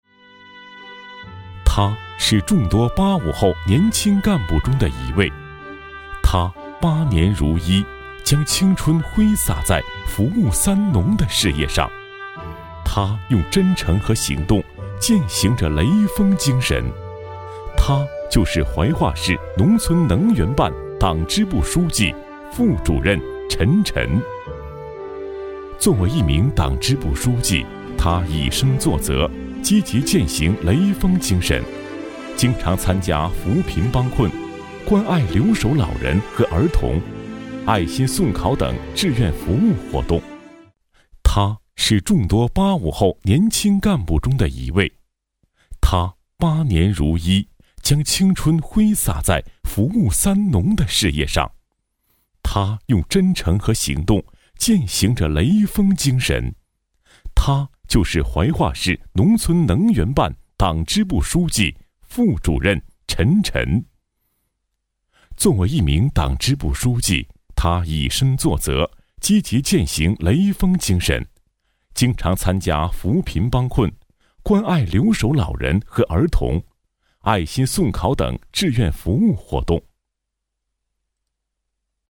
专题片/宣传片配音-纵声配音网
男22 - 干部（浑厚亲和） 浑厚 男22央视大气
男22 人物宣传-干部（浑厚亲和）.mp3